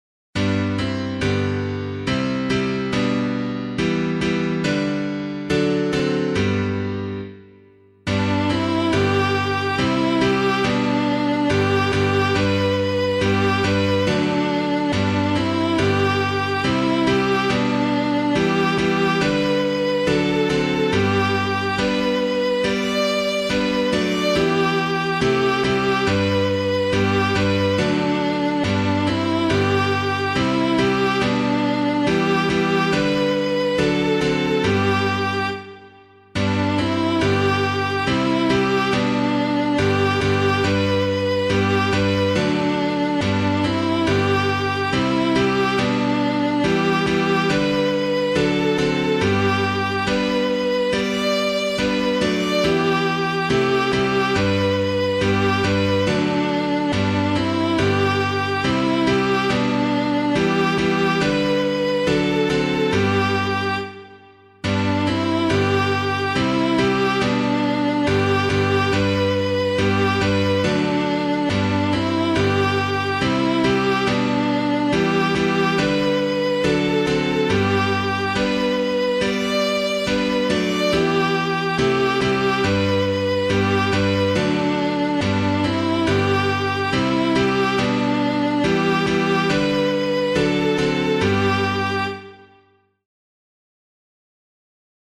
piano piano